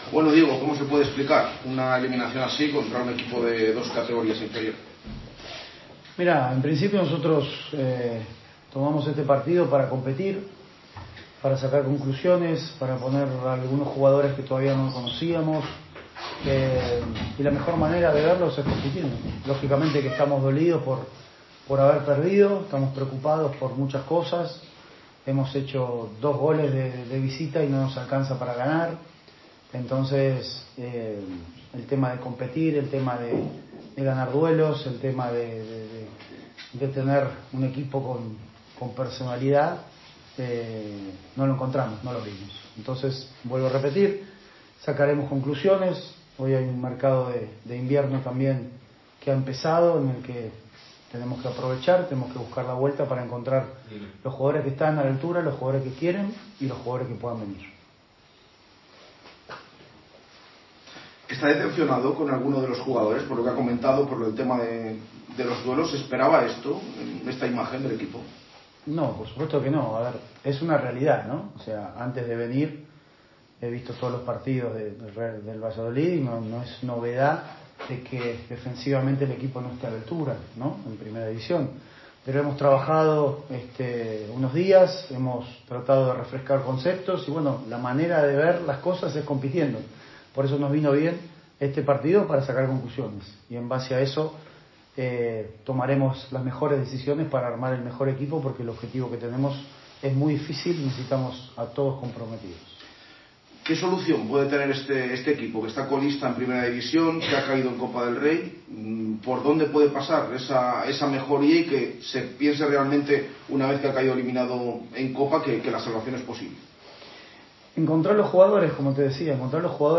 aquí la rueda de prensa completa